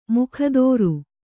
♪ mukhadōru